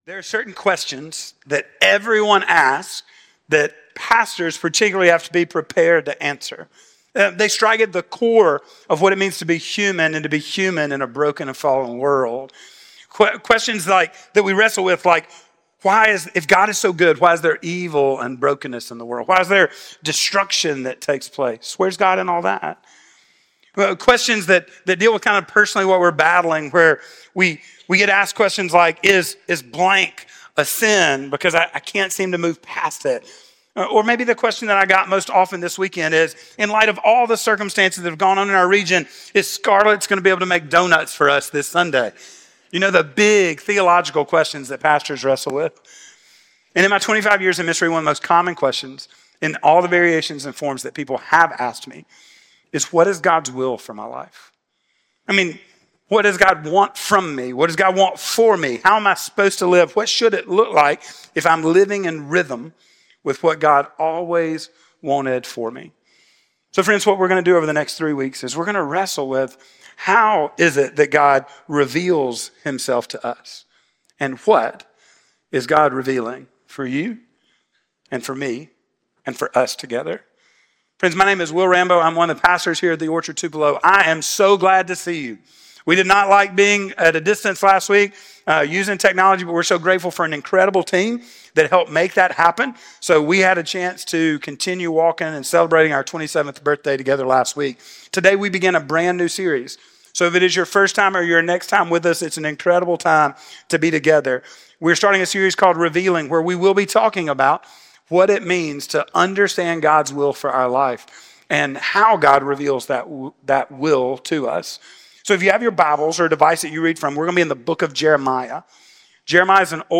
Sermon Series: Revealing